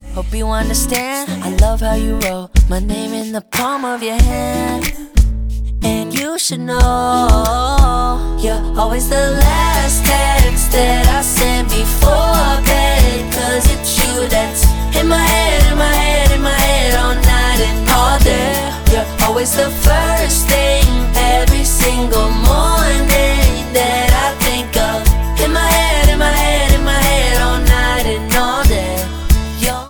• Pop